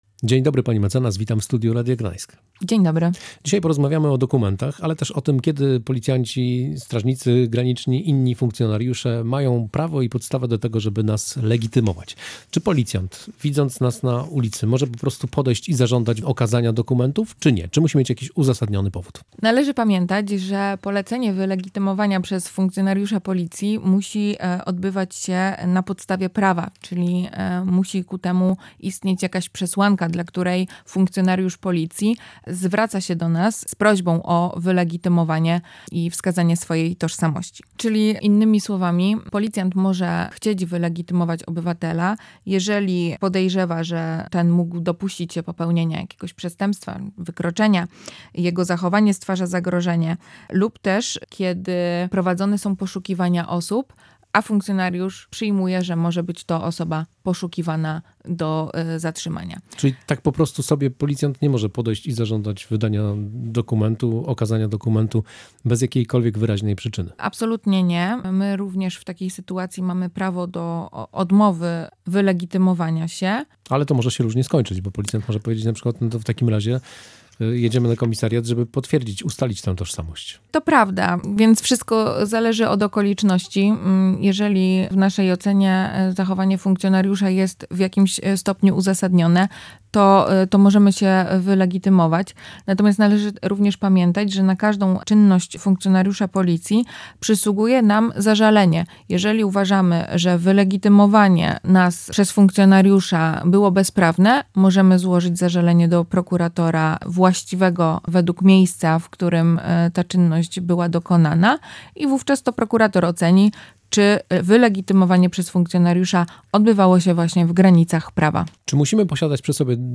Tłumaczy adwokat